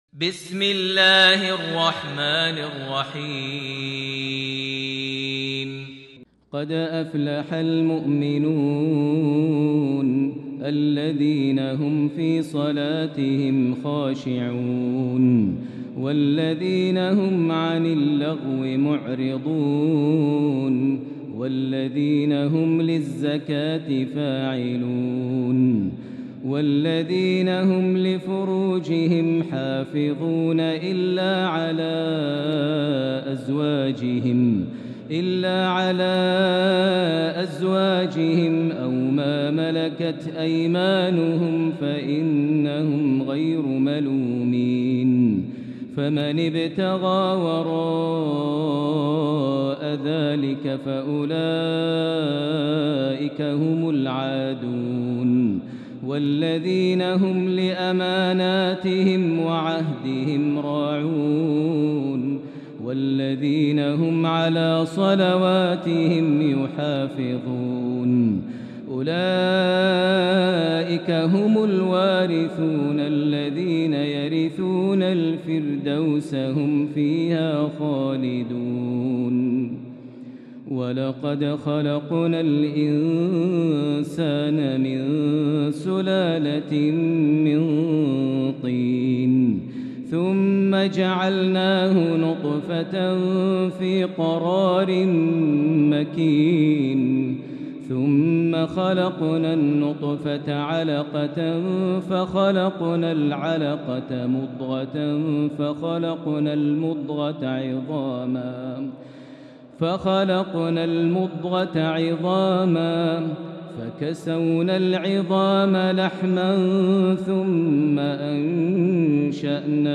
سورة المُؤمنون كاملة > مصحف الشيخ ماهر المعيقلي (2) > المصحف - تلاوات ماهر المعيقلي